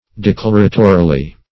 declaratorily - definition of declaratorily - synonyms, pronunciation, spelling from Free Dictionary Search Result for " declaratorily" : The Collaborative International Dictionary of English v.0.48: Declaratorily \De*clar"a*to*ri*ly\, adv.